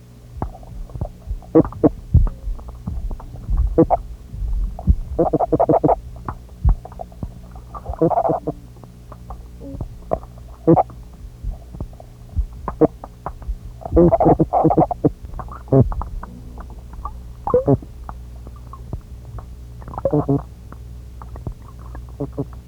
Sounds from the Fish and Mowbray archive at URI
(=Arius) felis, Sea catfish.
Sample 1 (3,908 KB) - Fish caught on hook-and-line in Florida and South Carolina, bandpass filter 1200 Hz. Snapping shrimp and toadfish in the background.
galeichthys_felis_1.wav